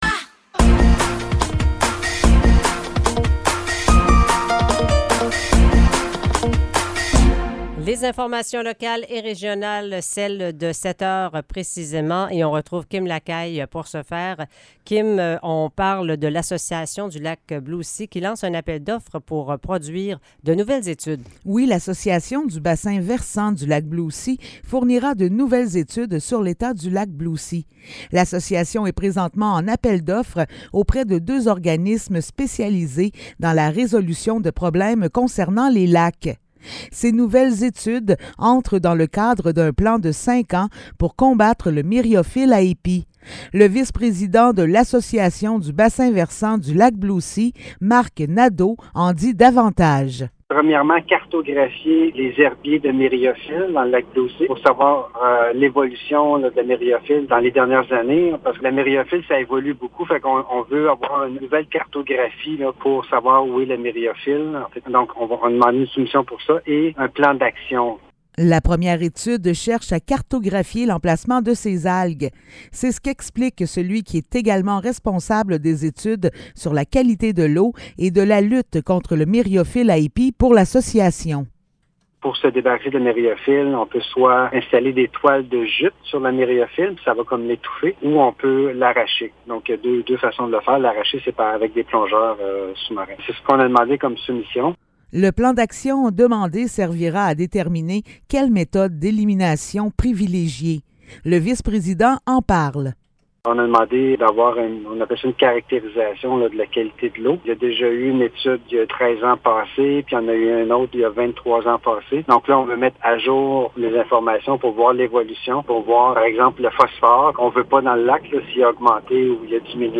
Nouvelles locales - 7 septembre 2023 - 7 h